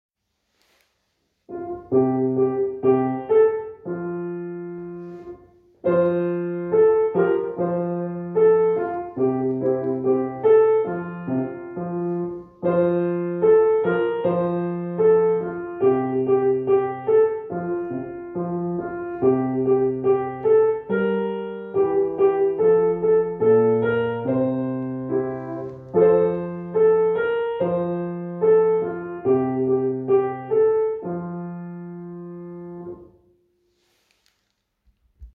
Klavierbegleitung „Hänsel und Gretel“Herunterladen